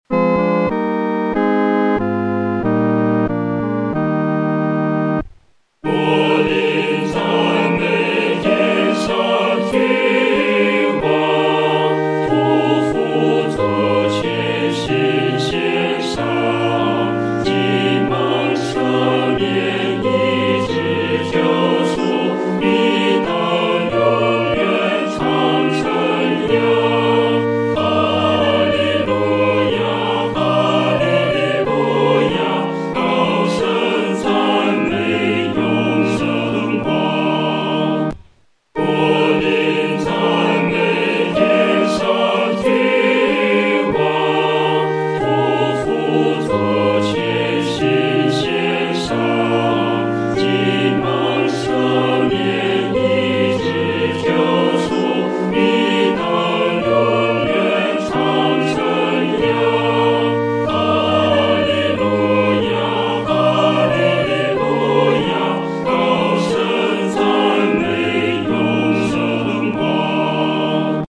合唱
本首圣诗由网上圣诗班 (南京）录制